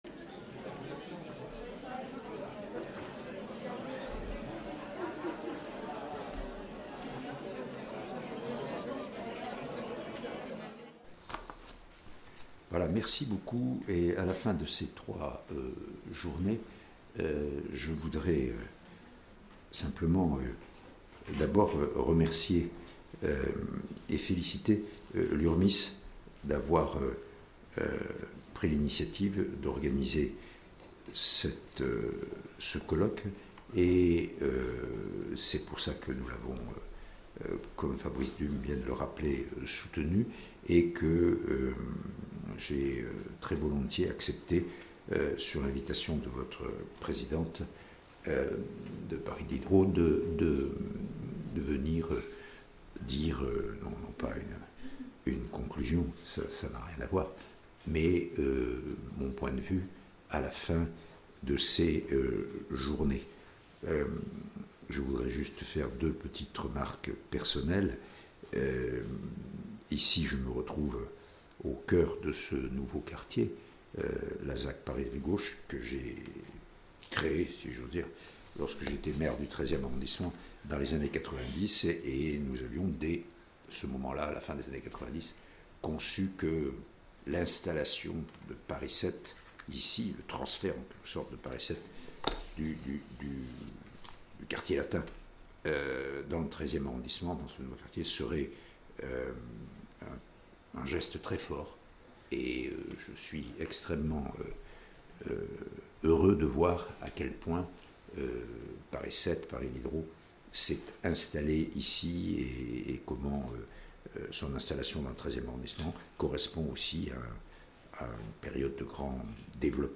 Avec Jacques Toubon, Défenseur des Droits.